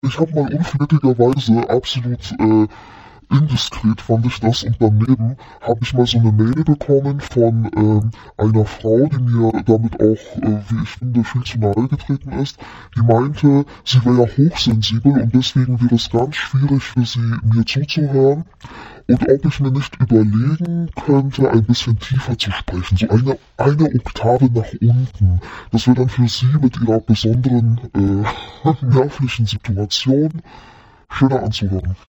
Eine Oktave tiefer ist aber schon ganz schön viel!